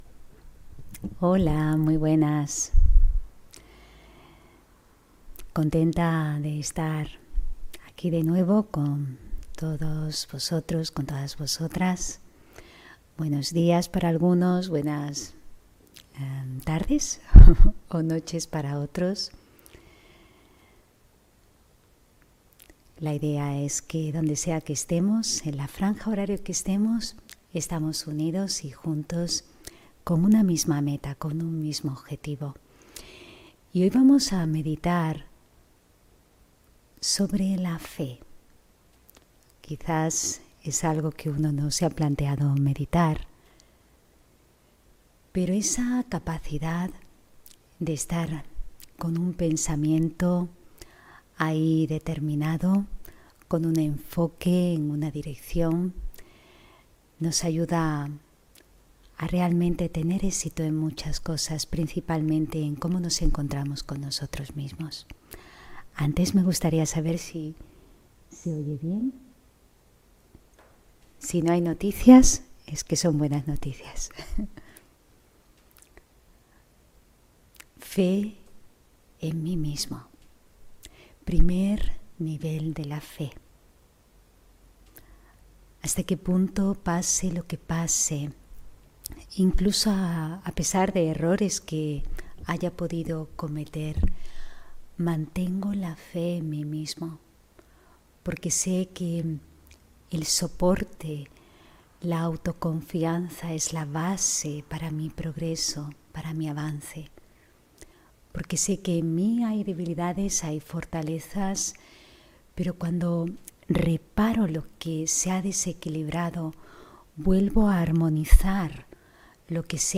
Meditación de la mañana Raja Yoga: La fe en tu meta (22 Diciembre 2020)